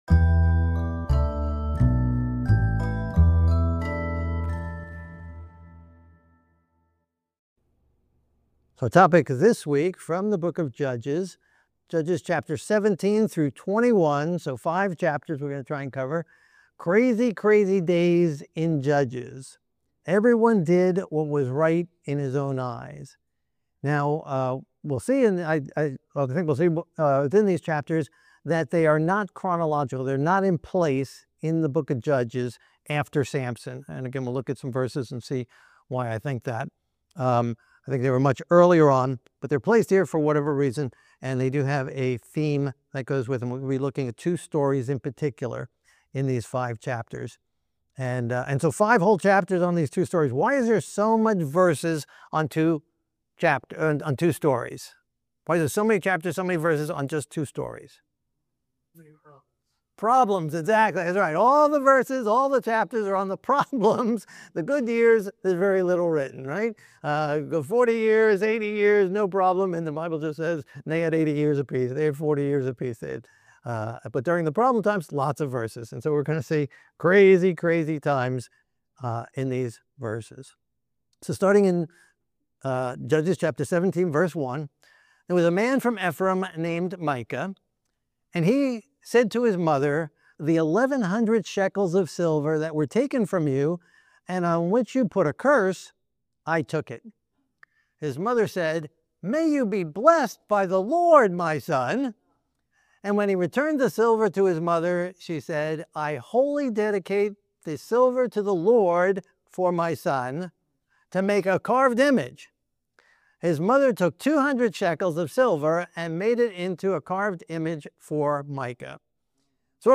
The subchannels of this channel contain powerful, heart moving verse by verse sermon series on various books of the Bible such as Daniel, Genesis, Esther, Ezra, Ezekiel, Isaiah, Jeremiah, and more being added all the time.